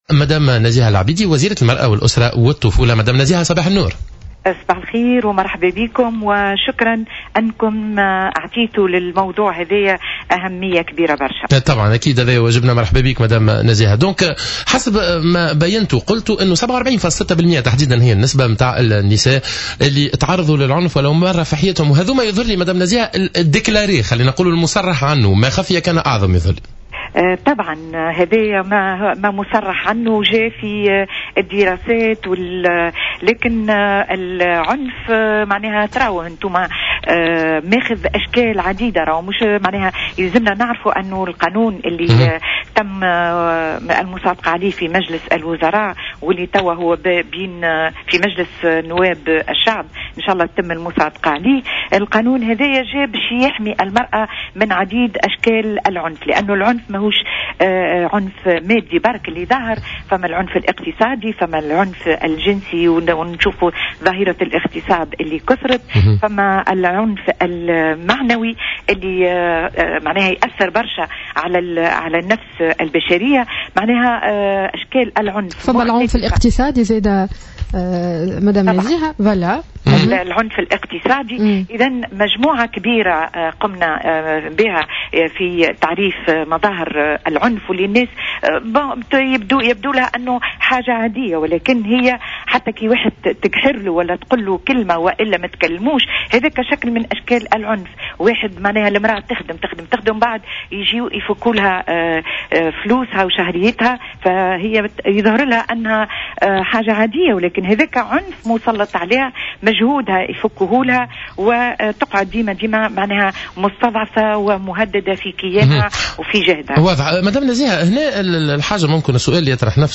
قالت وزيرة المرأة نزيهة العبيدي في مداخلة لها اليوم الخميس في صباح الورد على الجوهرة "اف ام" تعليقا على أدائها لزيارة فجئة متنكرة في "سفساري" مؤخرا إنها ستستعمل كل الأساليب والطرق الممكنة لتؤدي عملها وتتابع وتراقب النقائص والإخلالات الموجودة.